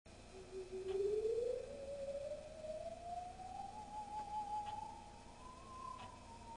The Theremin .................
You're watching an old horror movie, and as the creature approaches the teenagers sitting in the car, you suddenly hear from the screen a shrill, wavy sound, like a sharp, vibrating bow ... a mysterious sound that's hard to identify.
That's the sound of a theremin, and it's unlike any instrument you've ever heard or seen before.